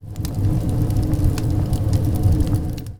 default_furnace_active.ogg